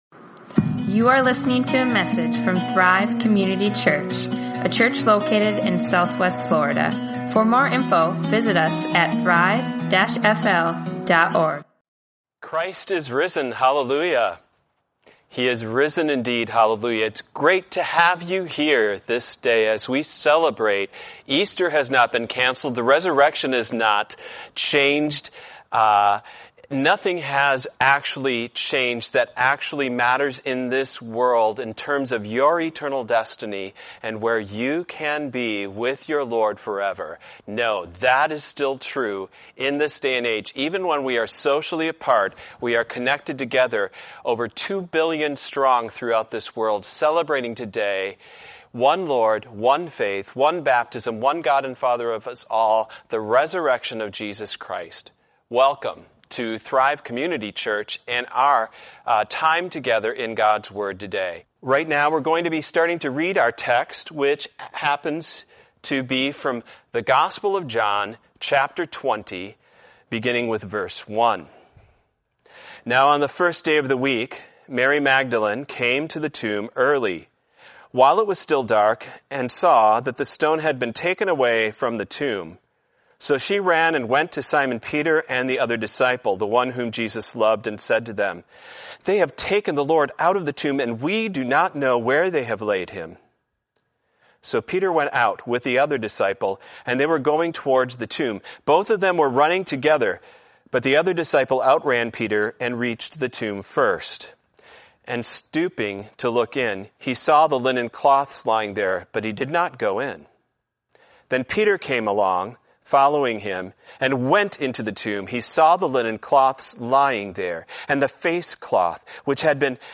Easter 2020 | Sermons | Thrive Community Church